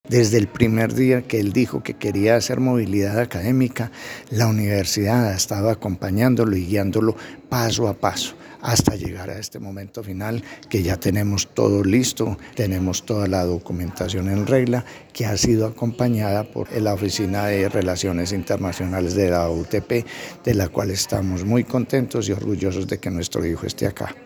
Esta semana se realizó un emotivo evento de despedida para los estudiantes que emprenderán su viaje académico al exterior.